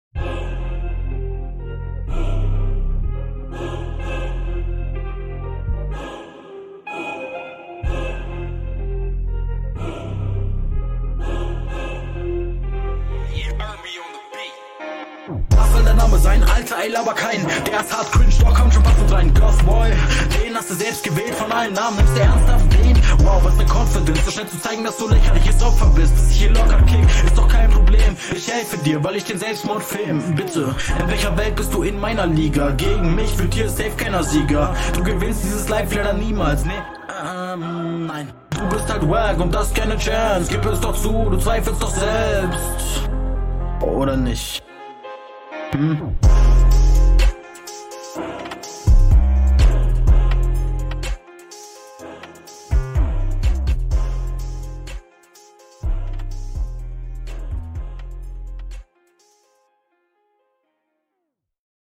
Auf dem Beat kommst du eindeutig besser und da passt der Trap-Style mit Doubletime sehr …
finde ich stärker als deine RR, vor allem ist die Quali viel, viel Besser.